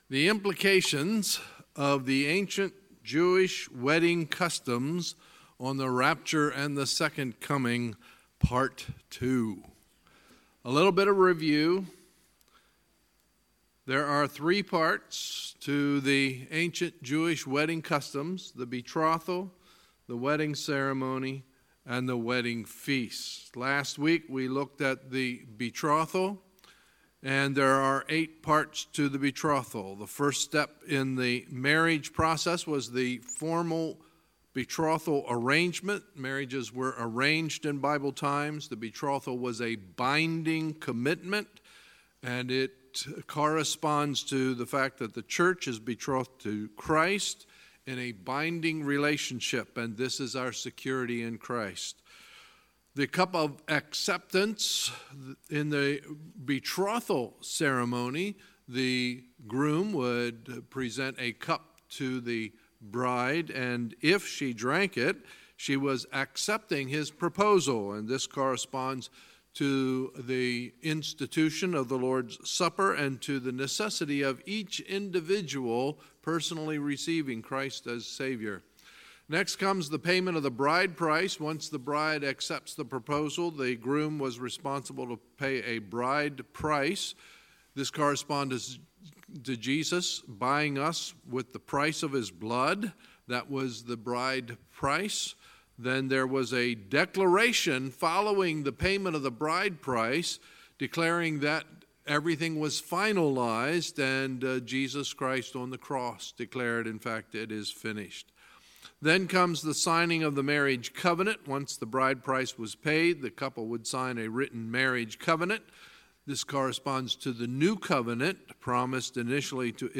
Sunday, June 24, 2018 – Sunday Evening Service